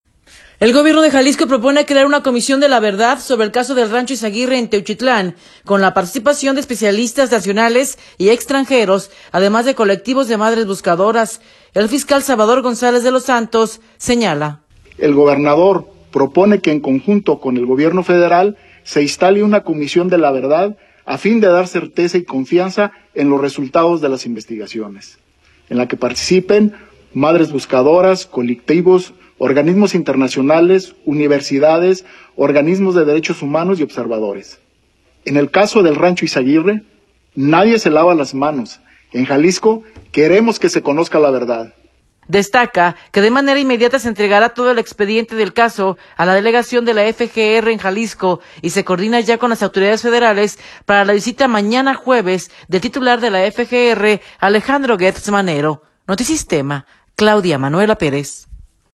El Gobierno de Jalisco propone crear una comisión de la verdad sobre el caso del rancho Izaguirre en Teuchitlán con la Participación de especialistas nacionales y extranjeros, además de colectivos de madres buscadoras. El fiscal, Salvador González de los Santos, señala.